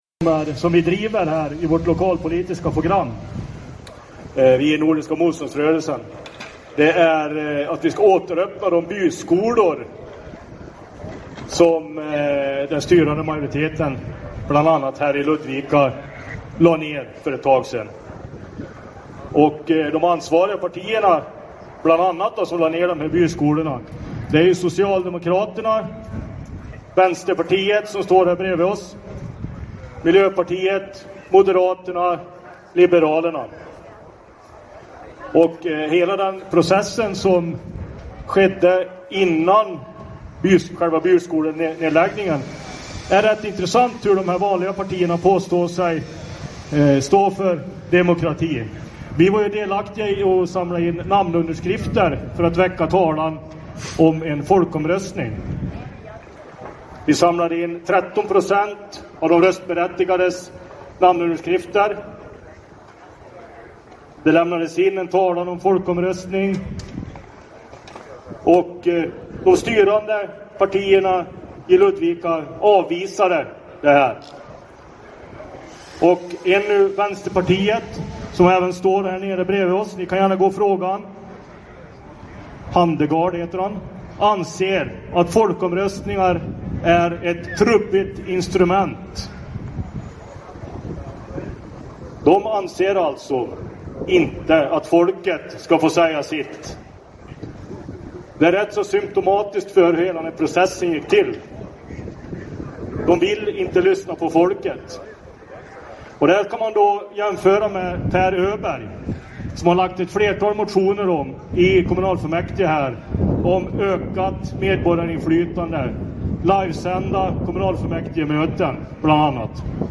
talar vid valtältet i Ludvika centrum